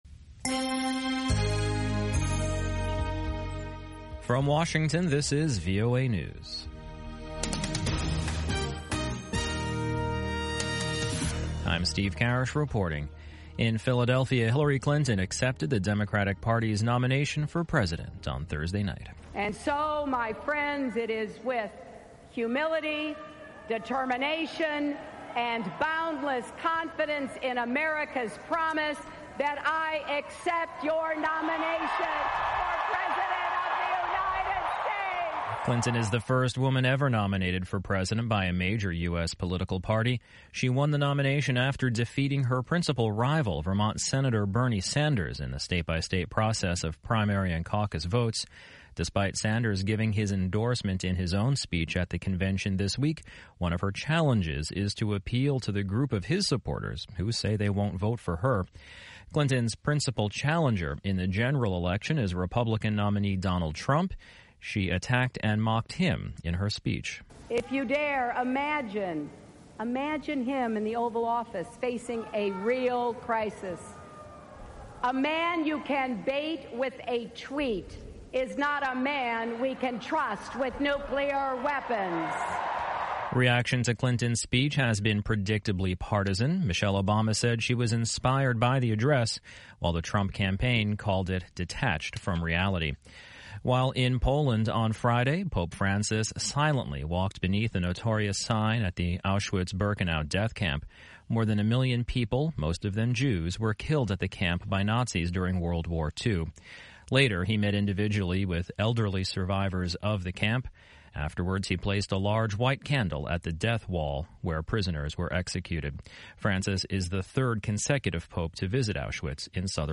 1300 UTC Newscast from July 29